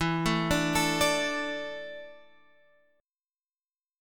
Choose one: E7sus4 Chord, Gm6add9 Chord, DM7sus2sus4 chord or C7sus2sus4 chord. E7sus4 Chord